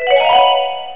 1 channel
HARP.mp3